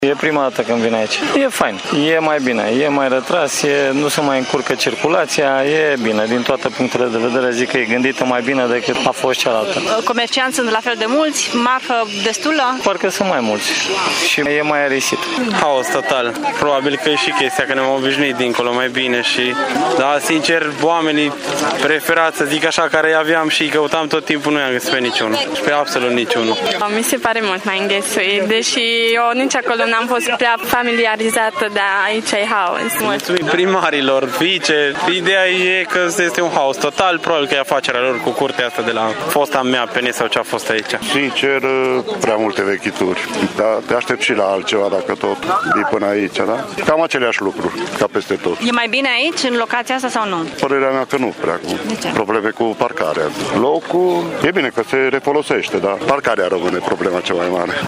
Târgumureșenii au venit în număr mare astăzi la Ocska, cum este cunoscută piața de zeci de ani iar unii dintre ei cred că e mai bine aici și mai ușor de ajuns.